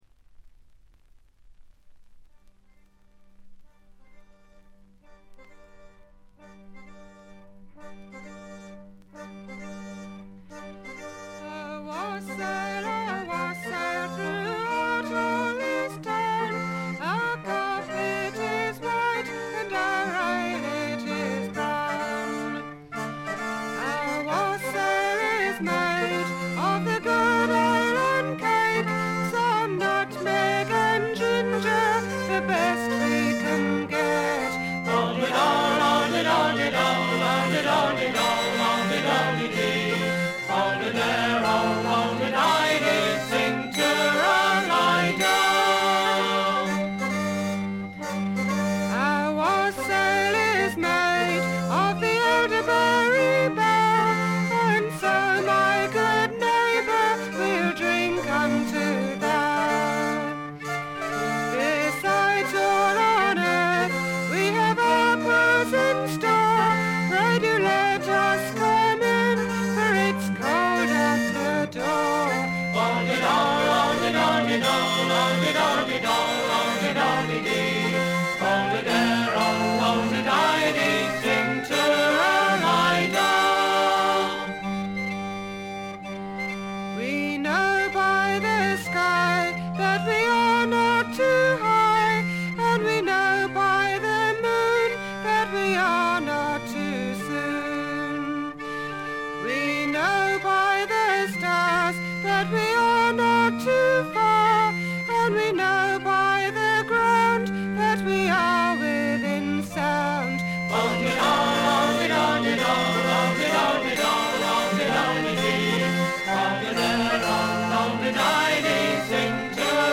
これ以外も軽い周回ノイズ、チリプチ、プツ音等多め大きめです。
試聴曲は現品からの取り込み音源です。